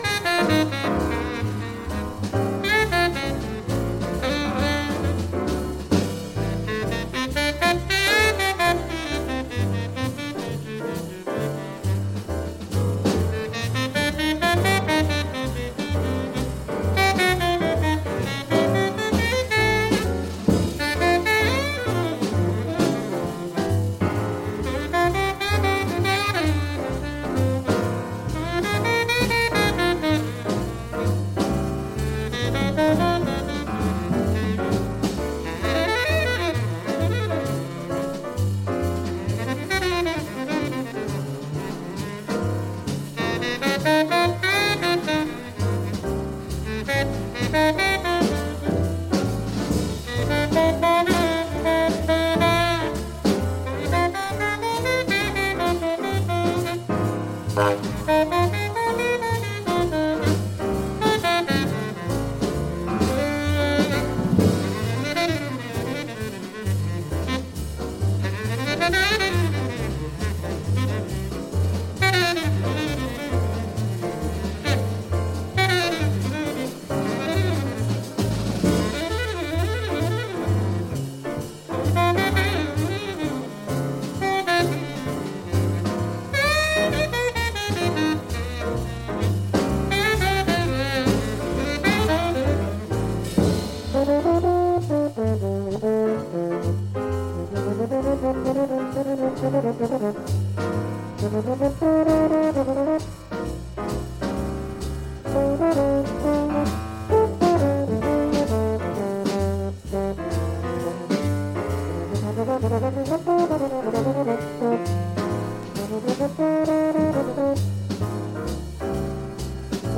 a new breed of psychedelic jazz